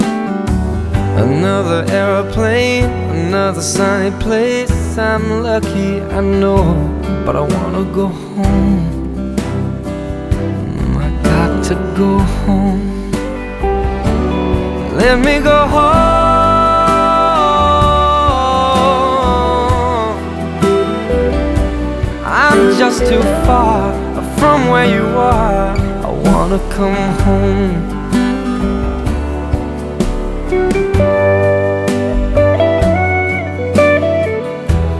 Жанр: Поп музыка / Рок / Джаз